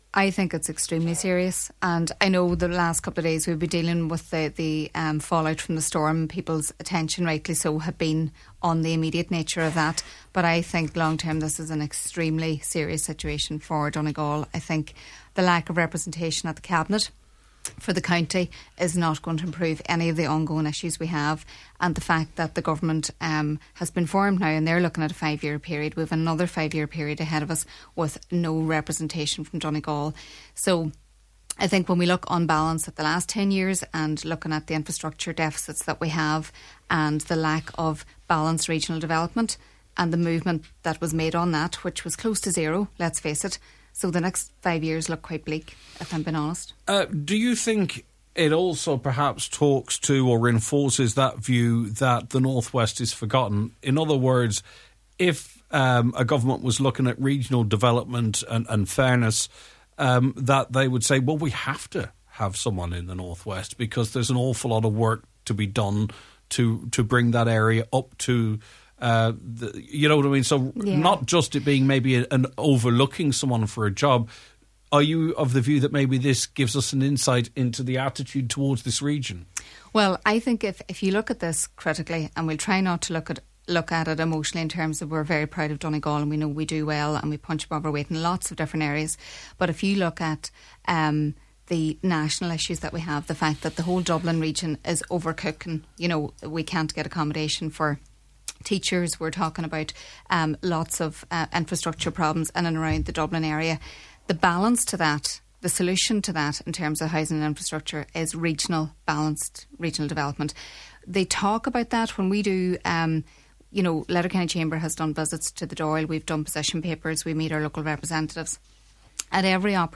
Speaking on today’s Nine til Noon Show, she said lip service is paid to the concept of balanced regional development, but there’s very little delivery………..